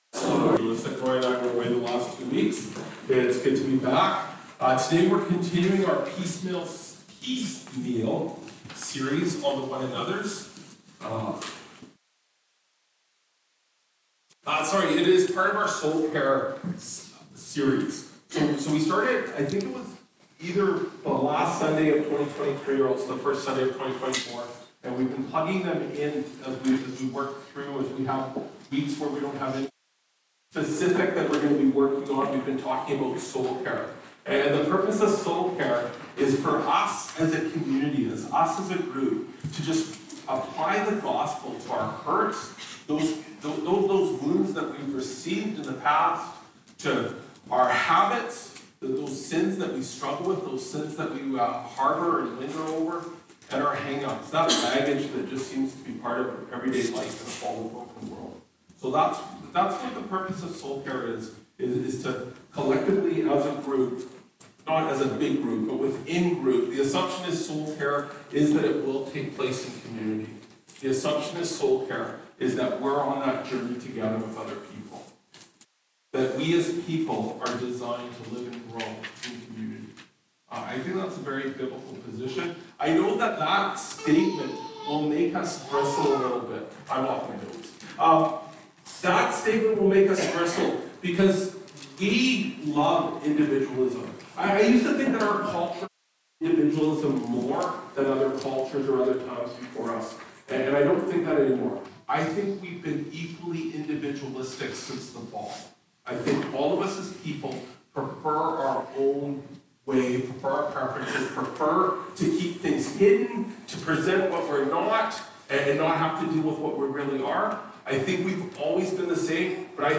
Aug 25, 2024 Encourage One Another (Hebrews 10:23-27) MP3 SUBSCRIBE on iTunes(Podcast) Notes Discussion Sermons in this Series This sermon was recorded in Salmon Arm and preached in both SA and Enderby.